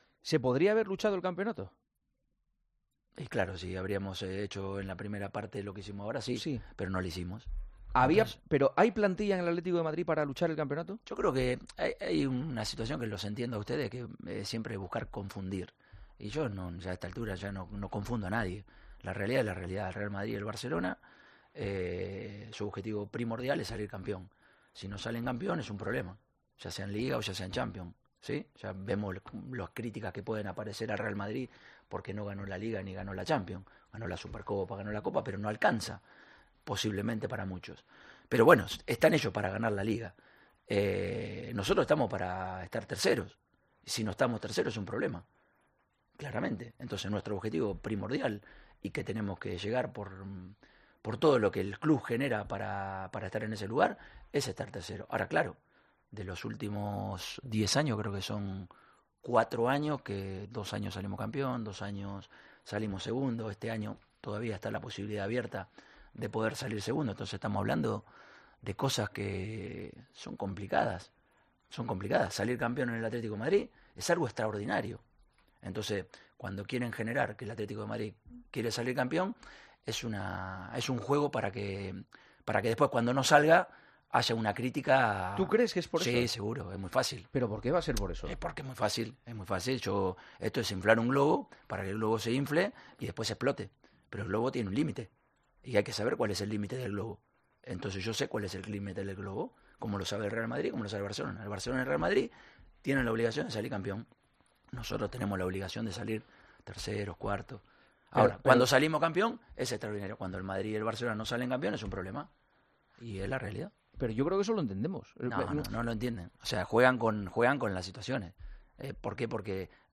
AUDIO: El Atlético de Madrid ha terminado la temporada mejor de lo que empezó, por eso Juanma Castaño cuestiona al 'Cholo'